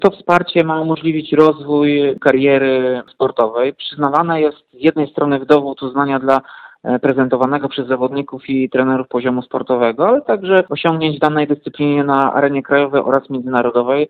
– Stypendia, to forma wsparcia dla zawodników, i trenerów – mówi Tomasz Andrukiewicz – prezydent Ełku.